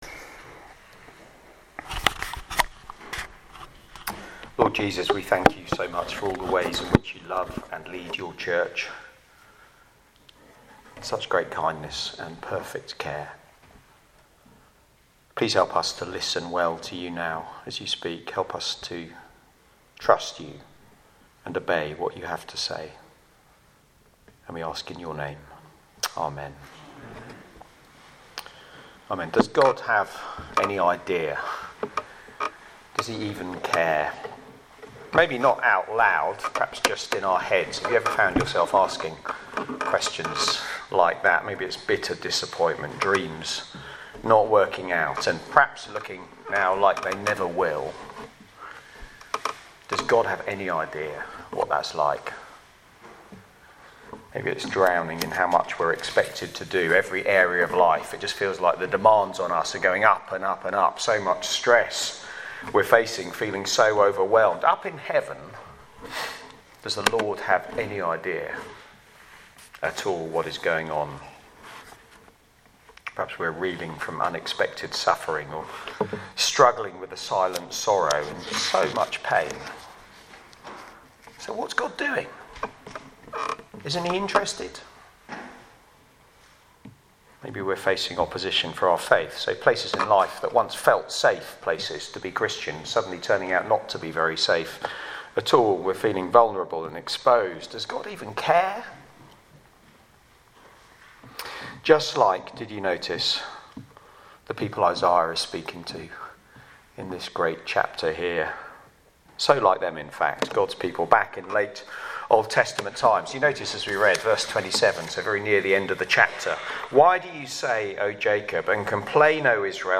Media for Barkham Morning Service
Theme: Sermon